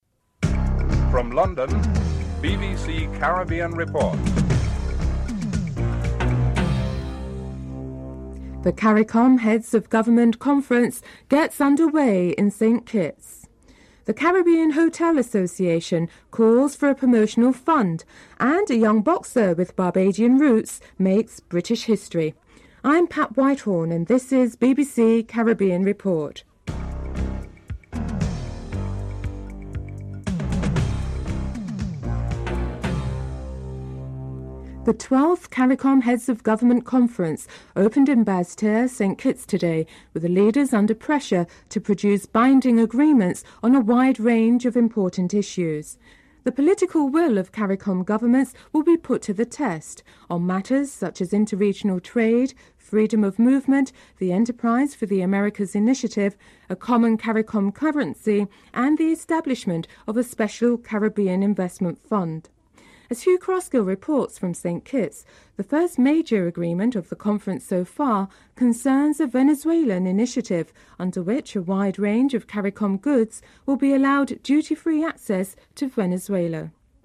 Interview with Duke McKenzie and his manager Mickey Duff (13:04-14:48)